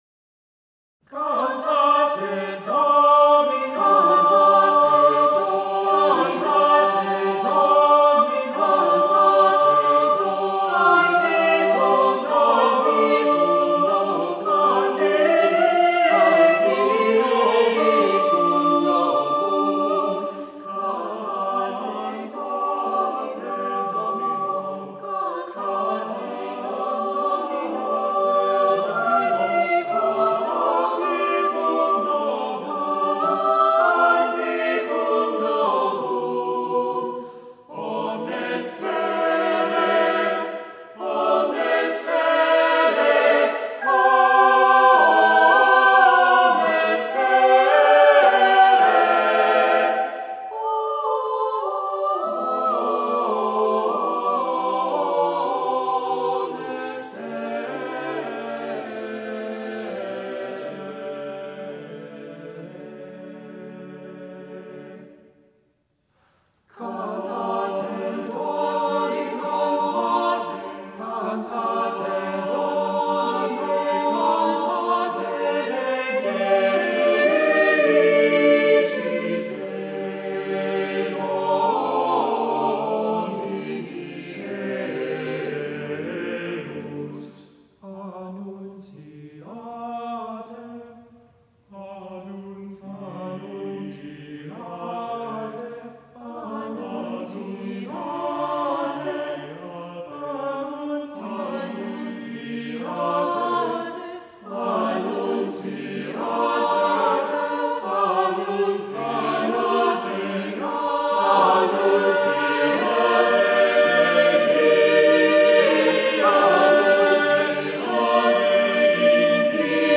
Voicing: SSATTB